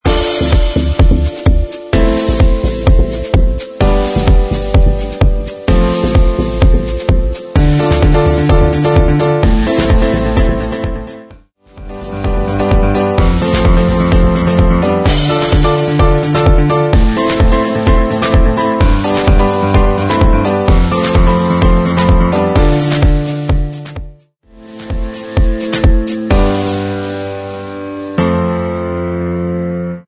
128 BPM
Upbeat Electronic